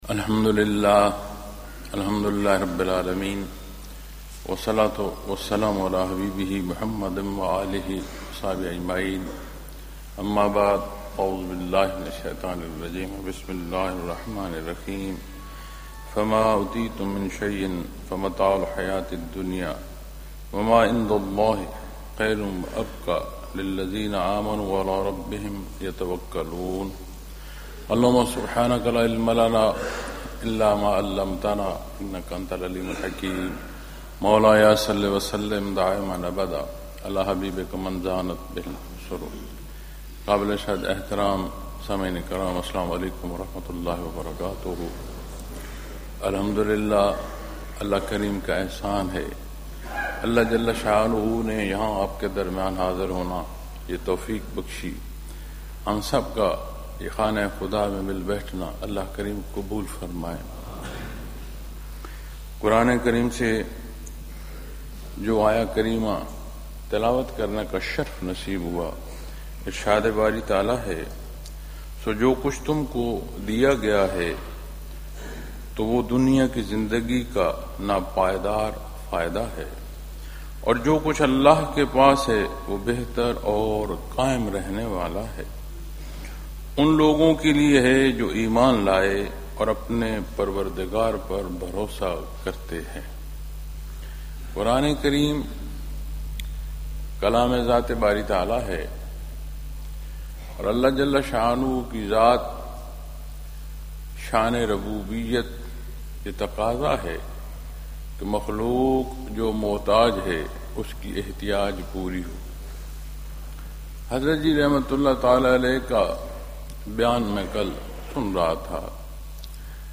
Lectures in Lahore, Pakistan